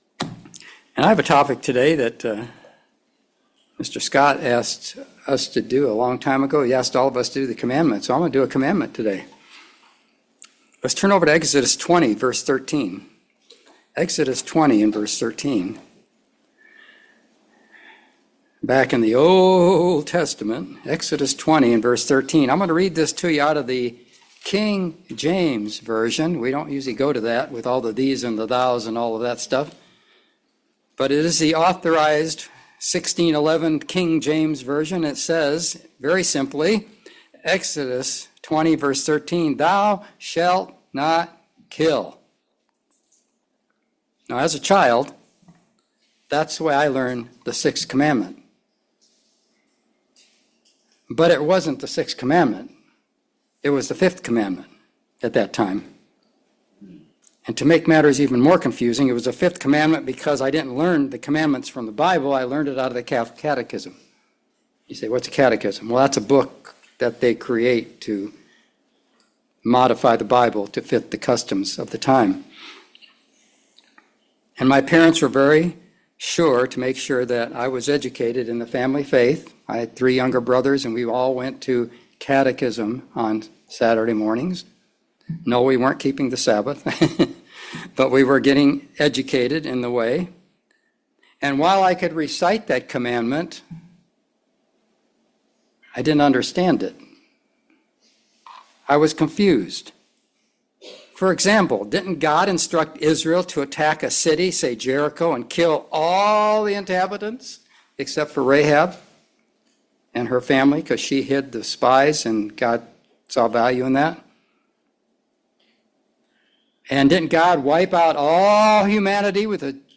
Sermons
Given in Raleigh, NC Greensboro, NC